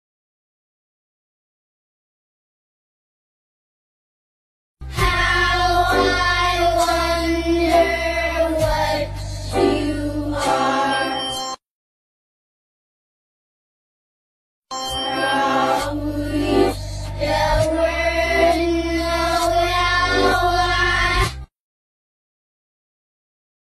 in reverse